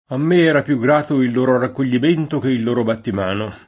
a mm% Hra pLu ggr#to il l1ro rakkol’l’im%nto ke il l1ro battim#no] (De Sanctis); avemmo le dimostrazioni, le petizioni ed i battimano [